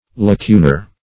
Lacunal \La*cu"nal\ (l[.a]*k[=u]"nal), Lacunar \La*cu"nar\
(l[.a]*k[=u]"n[~e]r), a.